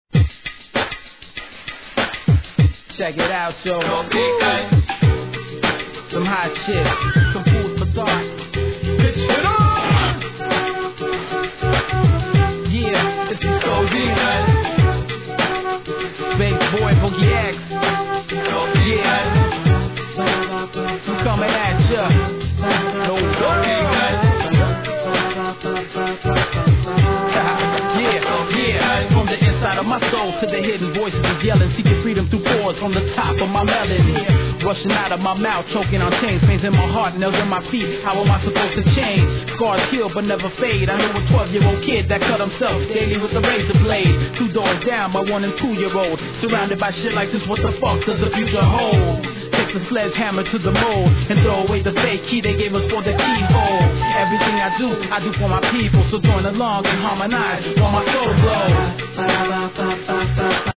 HIP HOP/R&B
ギターとバイオリンの哀愁メロディーが印象的な民族音楽風の一曲!